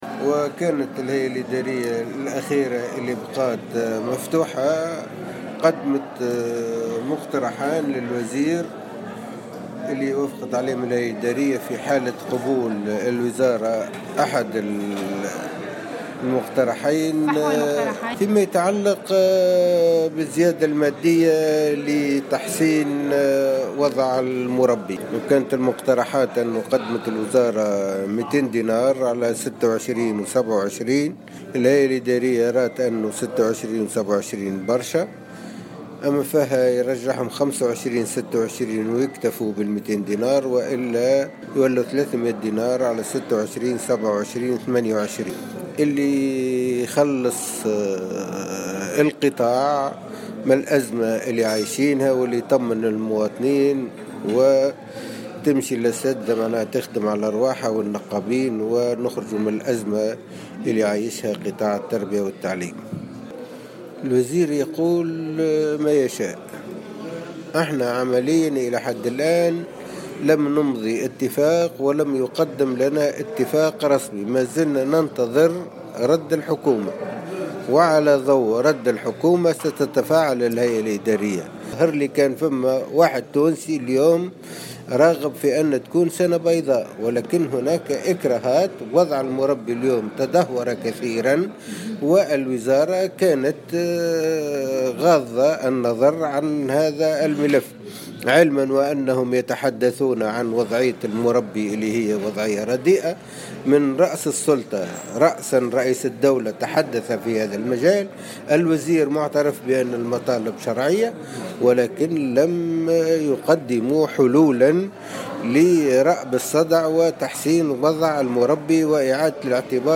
Déclaration